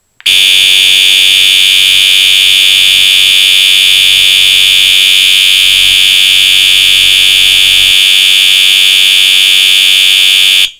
Notes: Makes 8 different tones: Bell, Hi-Lo, Chime, Single Stroke Bell, Steady Horn, Temporal Horn, Siren, and Slow Whoop.
U-MMT_Steady_Horn.mp3